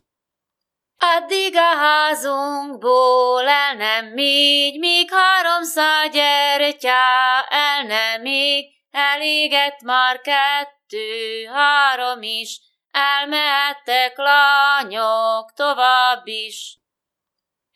Farsangolás alkalmával ezzel a dallal búcsúztak a háziaktól.
TípusII. Ünnepi szokások / 01. Farsang
TelepülésMedveshidegkút, Hidegkút [Studená]